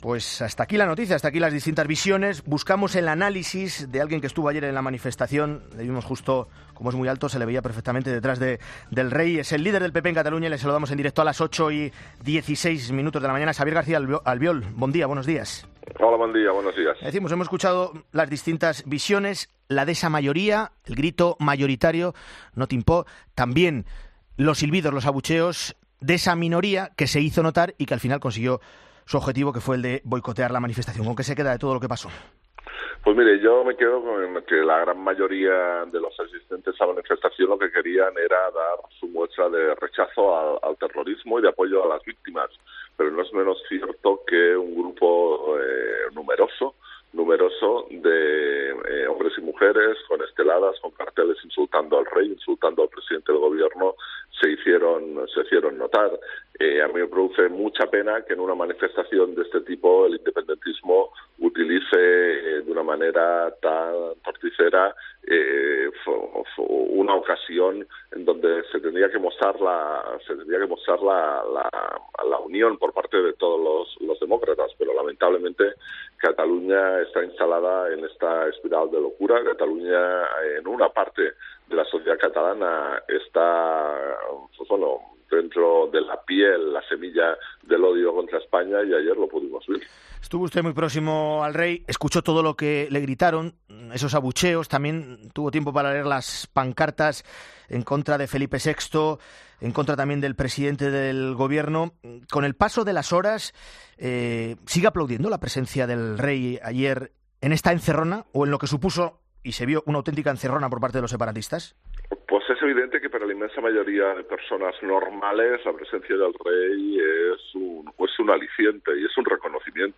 Xabier García Albiol, presidente del PP de Cataluña, en 'La Mañana de Fin de Semana'